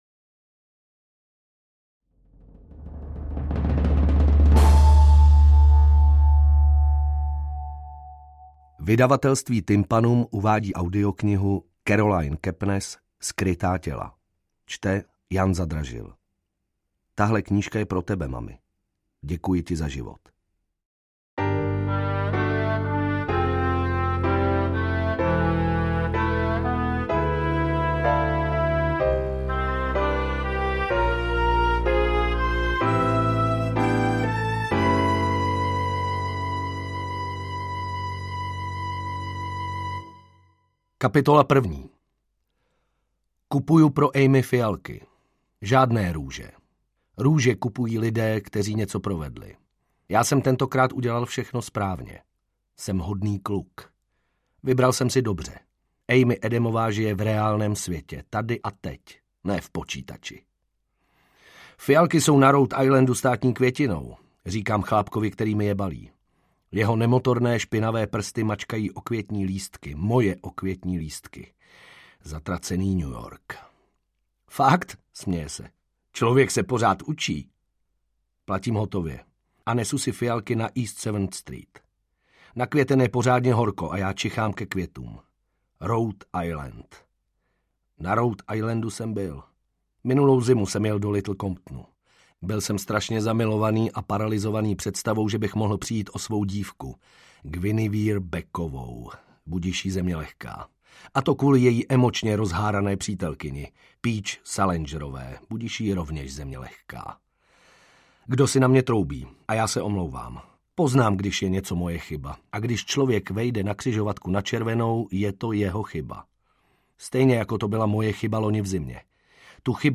AudioKniha ke stažení, 71 x mp3, délka 14 hod. 7 min., velikost 775,9 MB, česky